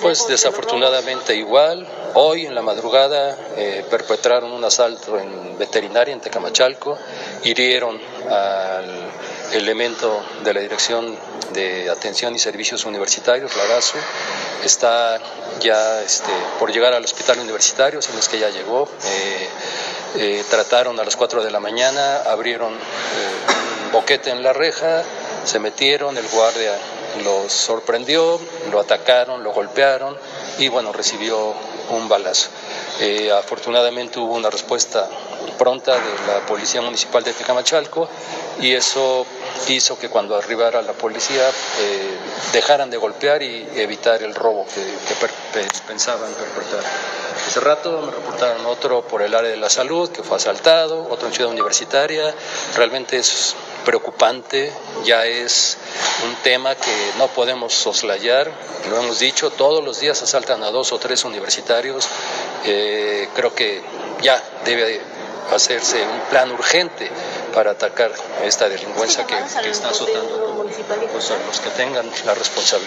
En entrevista posterior a la Primera Jornada Iberoamericana de Universidades en Centros Históricos Patrimoniales, Esparza Ortiz informó que hoy se presentó otro asalto entre la comunidad universitaria en el Aérea de la Salud y uno más en Ciudad Universitaria.